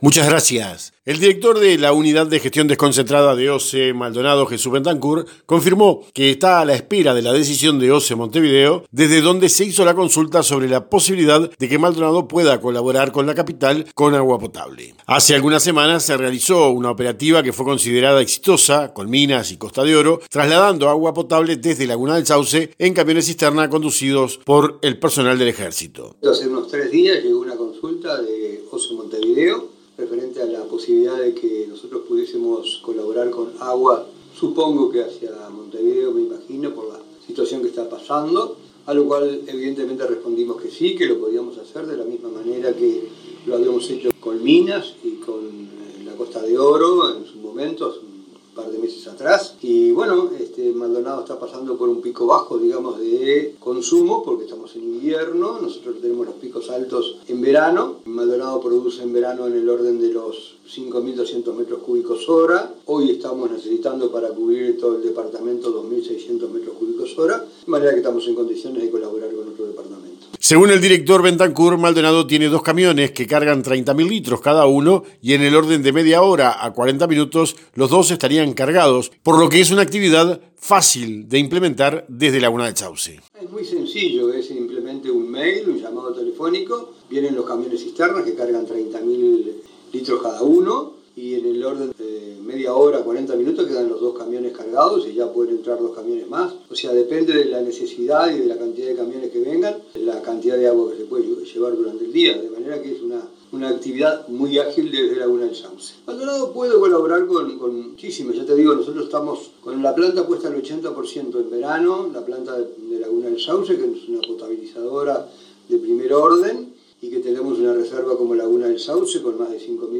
informe-agua.mp3